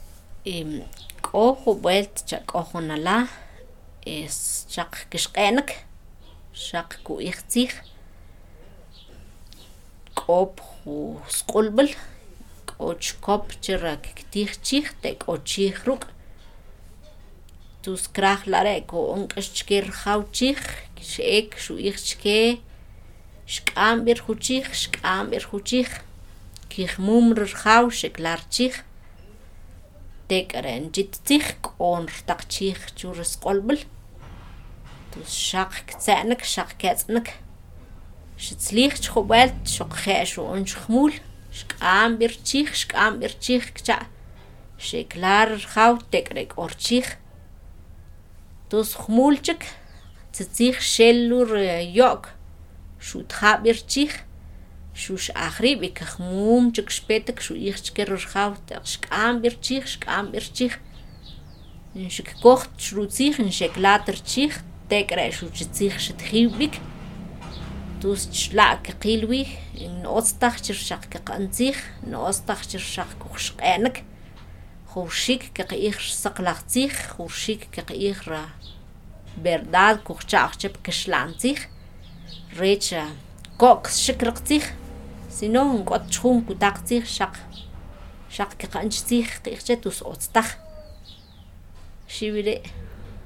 (Note that the audio files linked here have been edited to remove errors and repetitions, but the complete, original files, exactly as recorded, can be accessed through through the Archive of the Indigenous Languages of Latin America.)